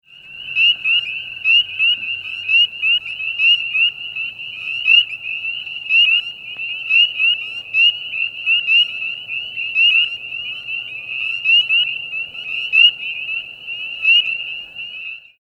Spring Peeper  - Pseudacris crucifer
Advertisement Calls
The advertisement call of Spring Peepers is a series of high-pitched single whistles repeated at about one second intervals. Occasionally there is a short trill.
sound  This is a 15 second recording of the advertisement calls of Spring Peepers recorded at night in April in Grundy County, Tennessee.